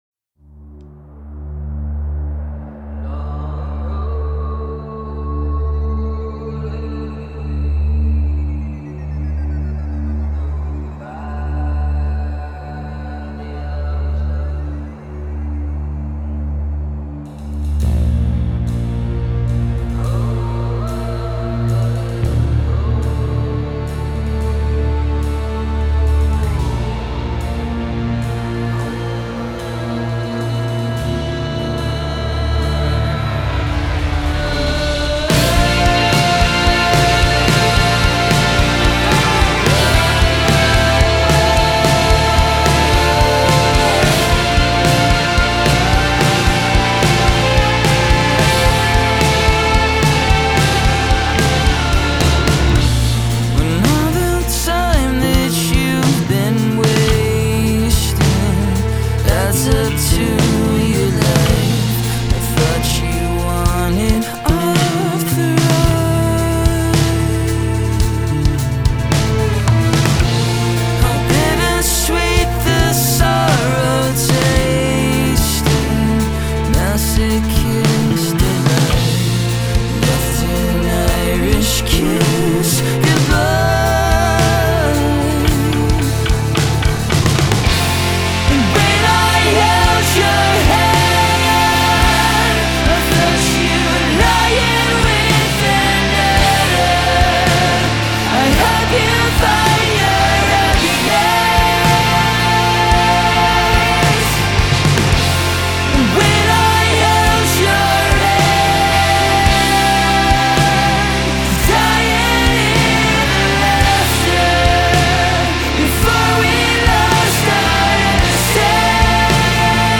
Rock, Anthemic